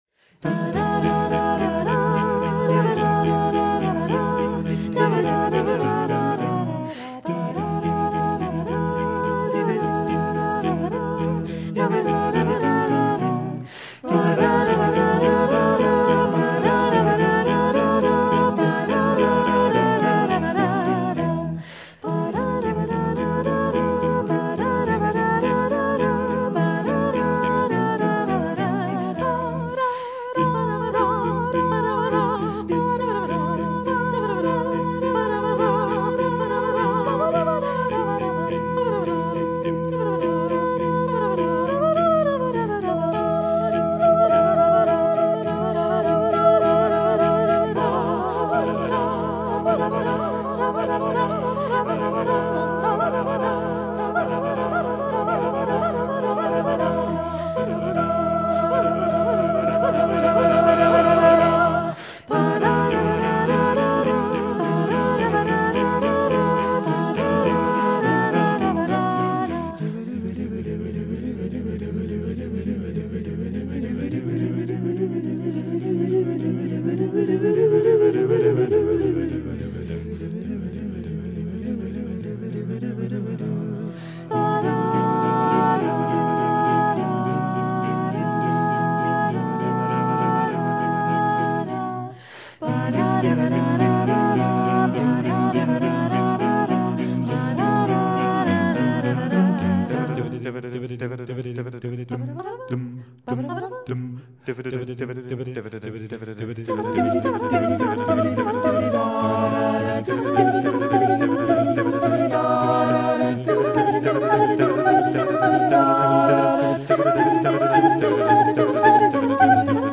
interpretada a capella